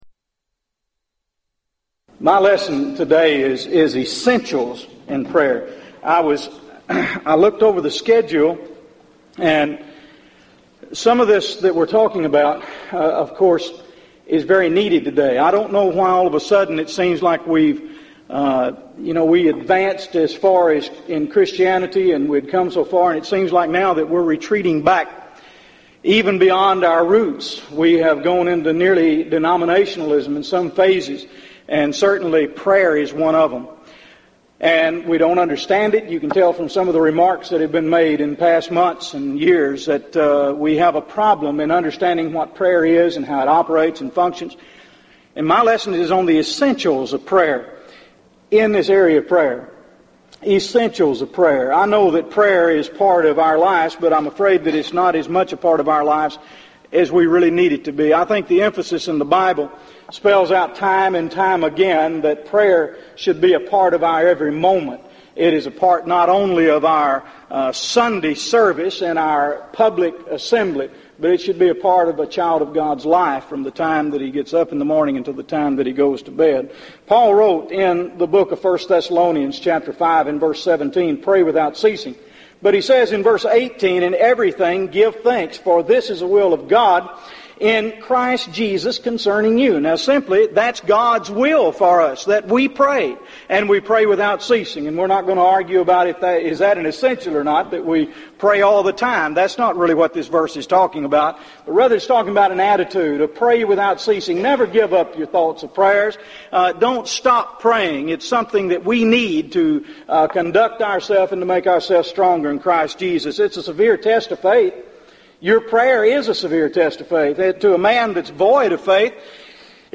Event: 1998 Gulf Coast Lectures Theme/Title: Prayer and Providence
lecture